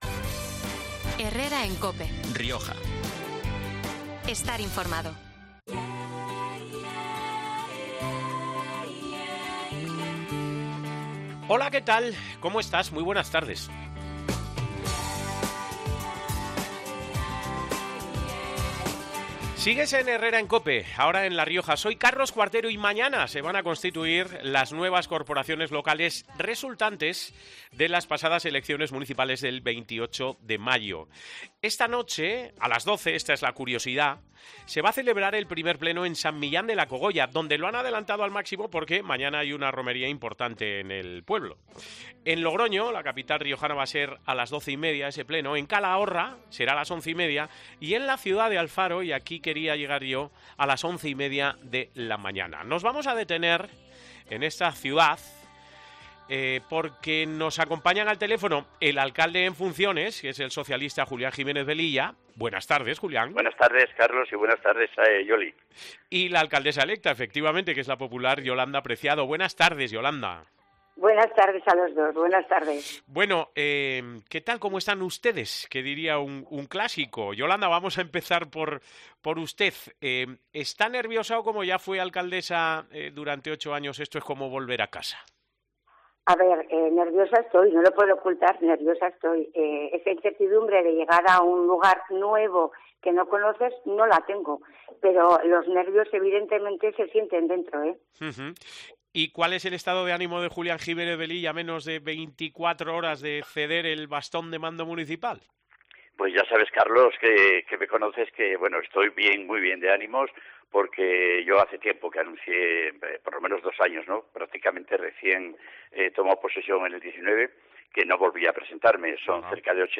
Pero este viernes, 16 de junio, el alcalde en funciones, que es el socialista Jiluán Jiménez Velilla, ha compartido micrófono con la primera edil electa de la ciudad, la popular Yolanda Preciado, y ambos han evidenciado que el traspado de poderes no tiene por qué ser algo incómodo, desagradable o violento.